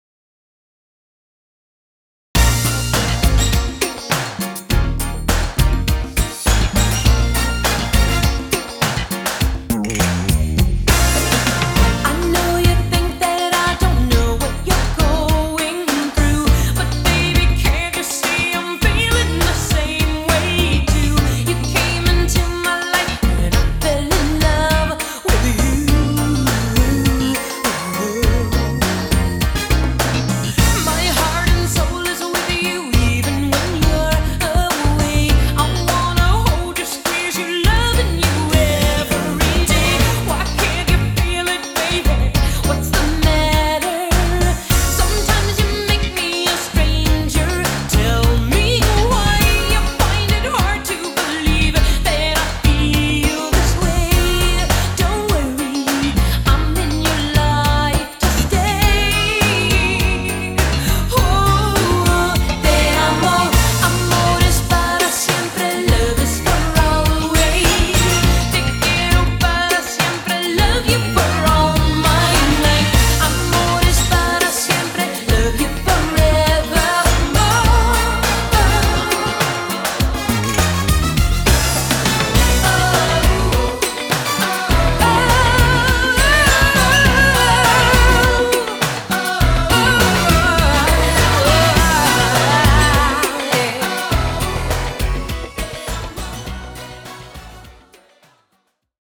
BPM102
Audio QualityMusic Cut